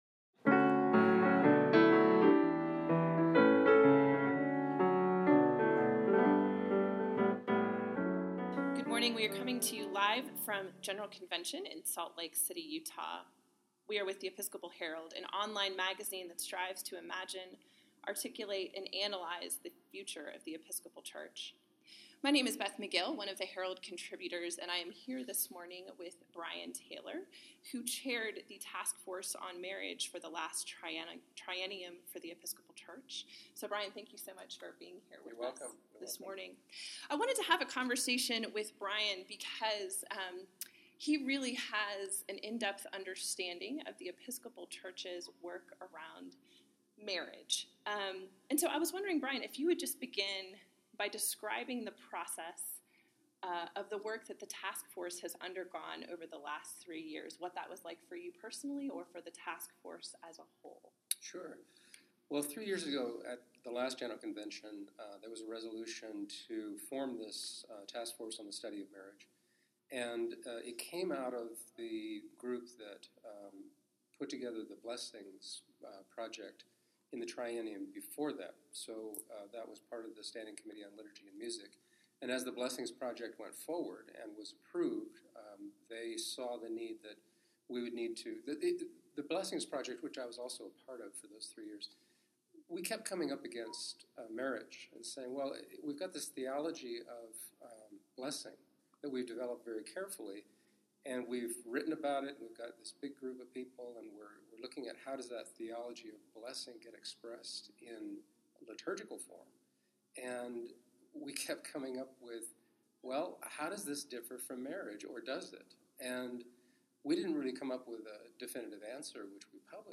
Sorry for the music in the background – General Convention is a busy place!